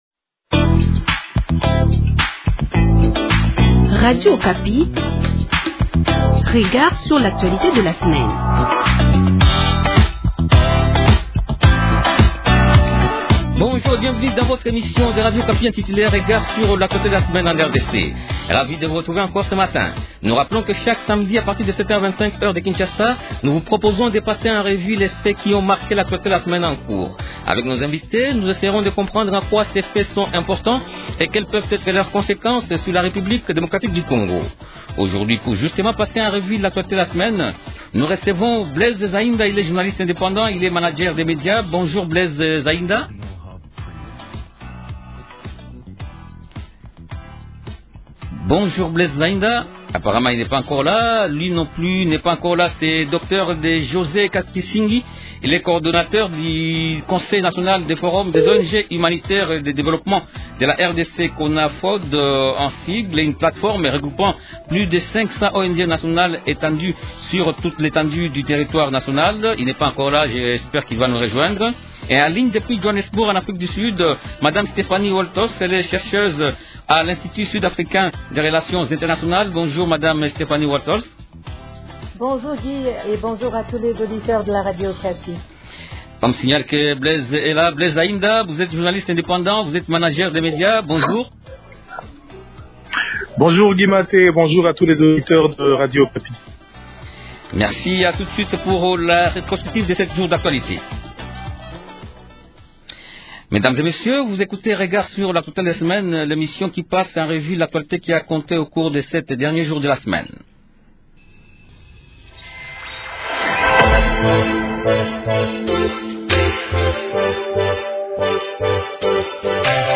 Journaliste indépendant. Il est Manager des médias.